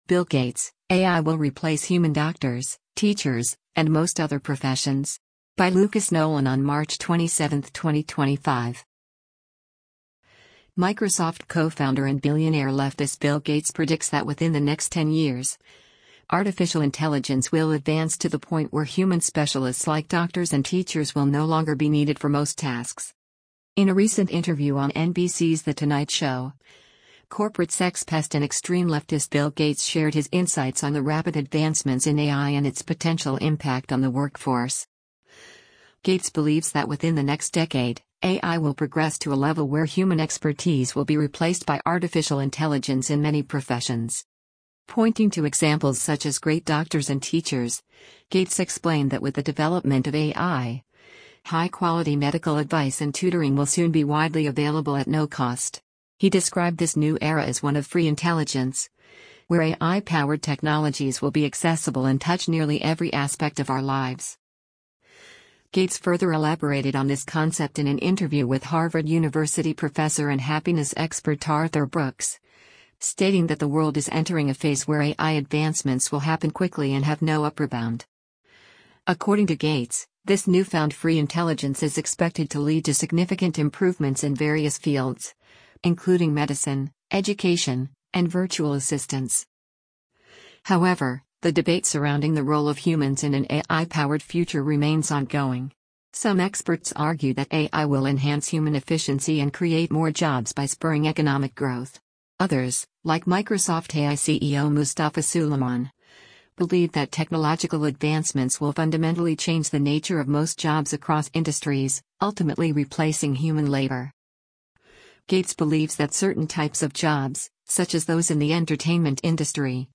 In a recent interview on NBC’s The Tonight Show, corporate sex pest and extreme leftist Bill Gates shared his insights on the rapid advancements in AI and its potential impact on the workforce.